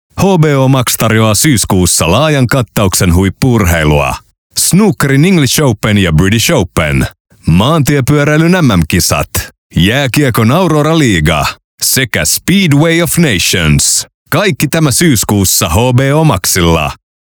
Voice color: deep